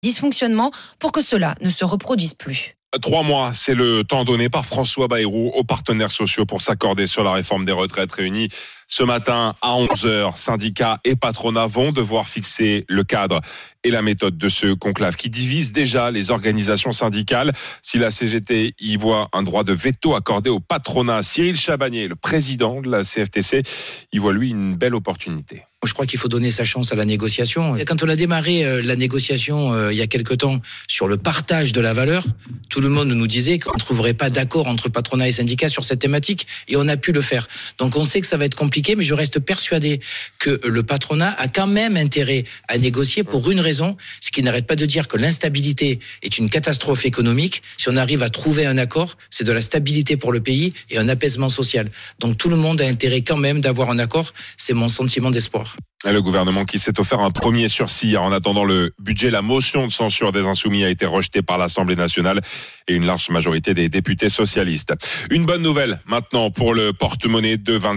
Après avoir échangé avec François Bayrou jeudi à Matignon, le président de la CFTC Cyril Chabanier a évoqué les contours et enjeux de la négociation sur les retraites sur BFM TV, qui débute ce vendredi 17 janvier.